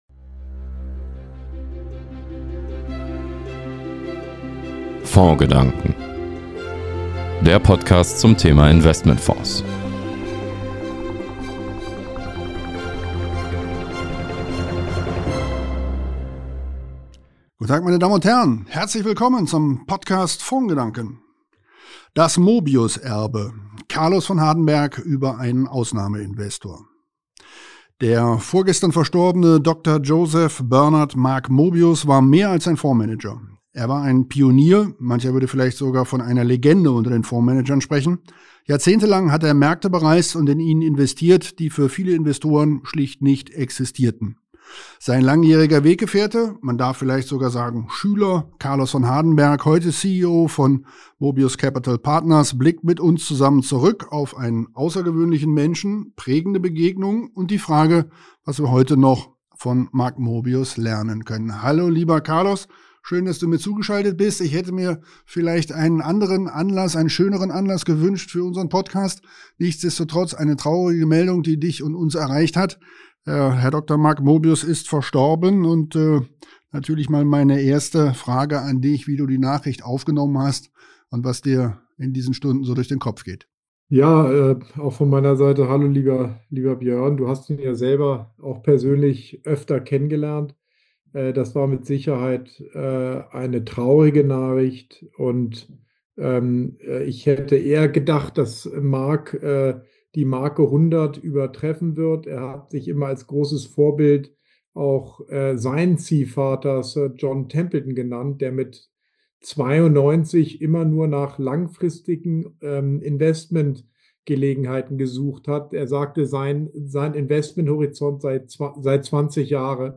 Aus unserer Sicht ein gleichermaßen einfühlsames wie lehrreiches Gespräch für Anleger.